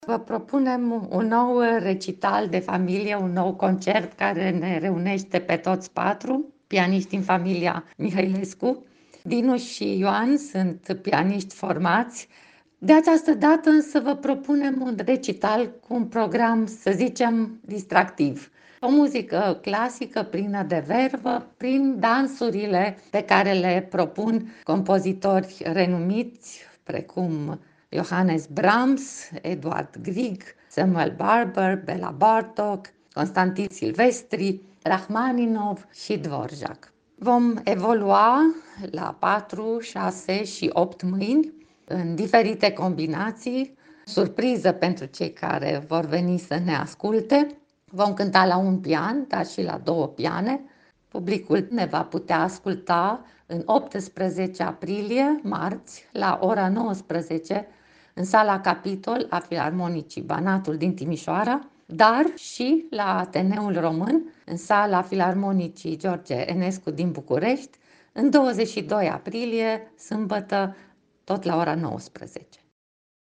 interviuri, pentru Radio Timișoara, cu pianiștii